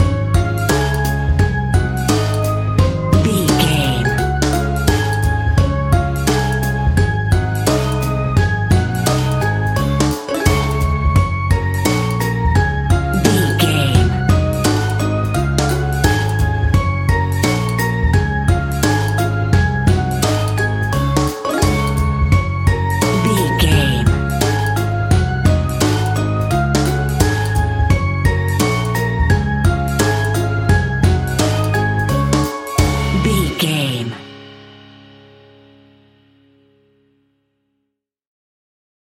Ionian/Major
D♭
childrens music
fun
childlike
cute
happy
kids piano